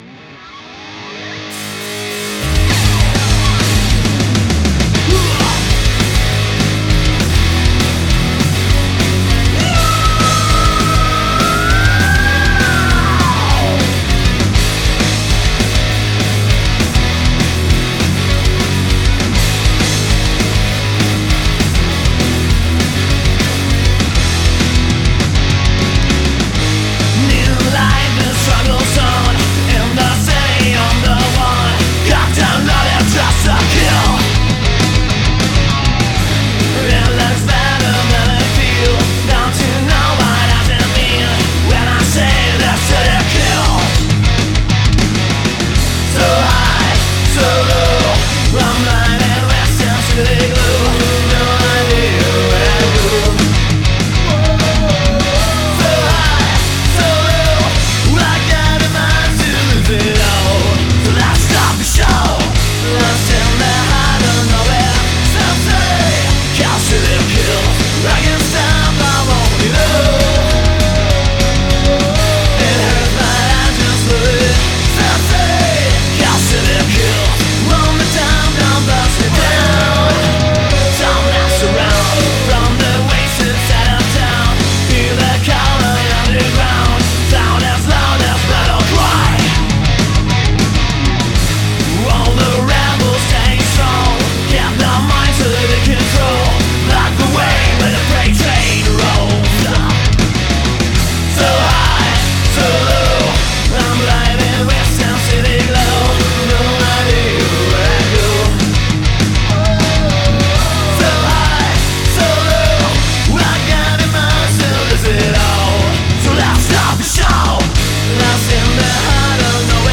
Genre: hardrock.